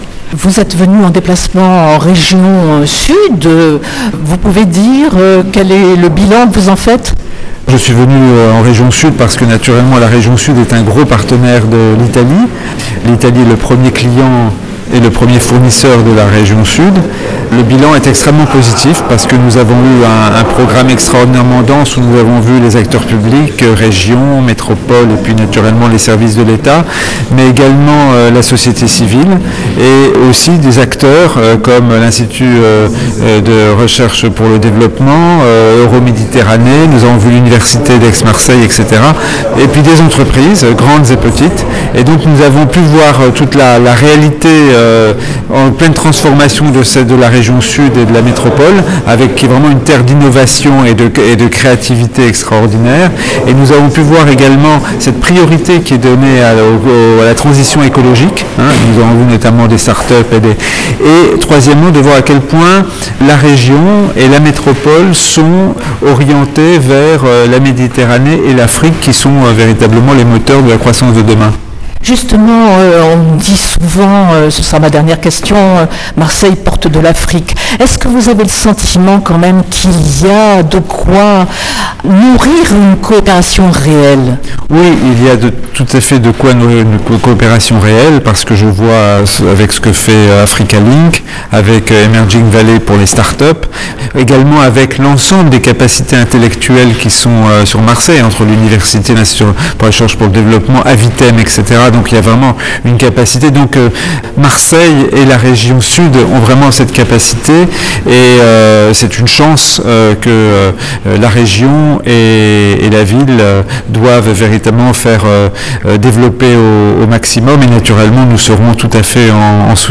son_copie_petit-382.jpgEntretien avec Christian Masset, ambassadeur de France en Italie